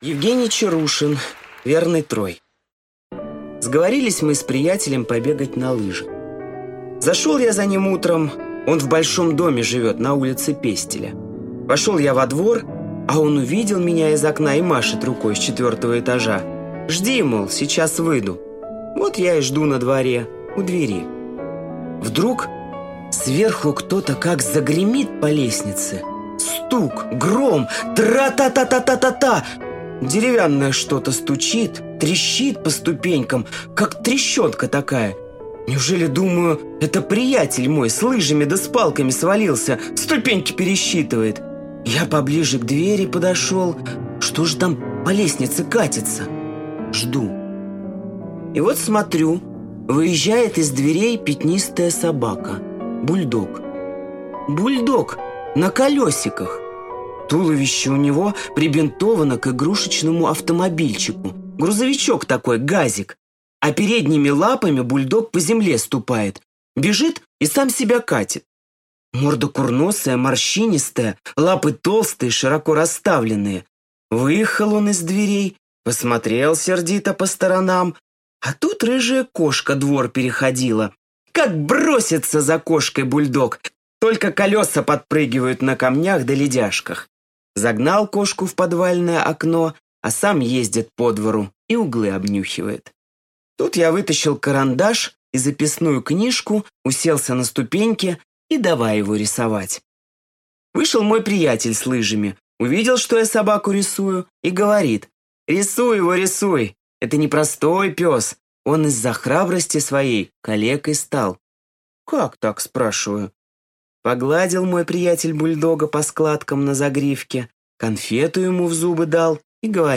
Аудиорассказ «Верный Трой»